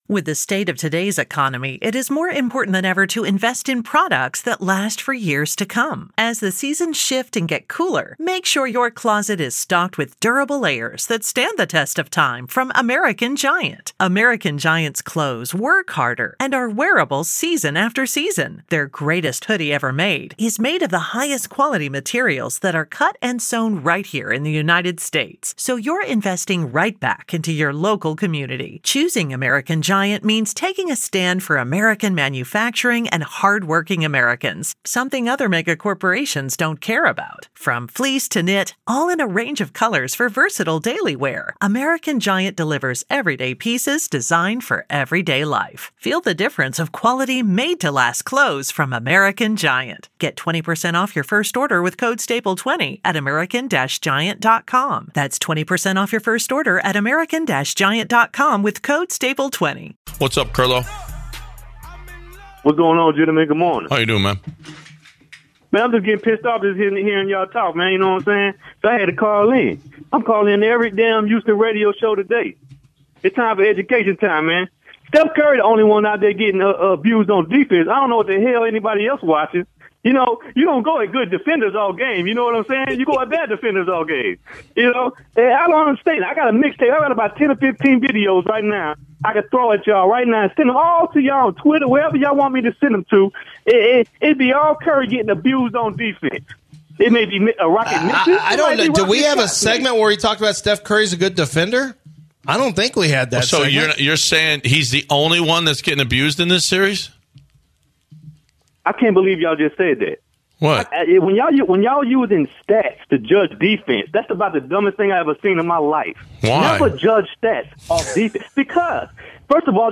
Delusional Rockets fan argues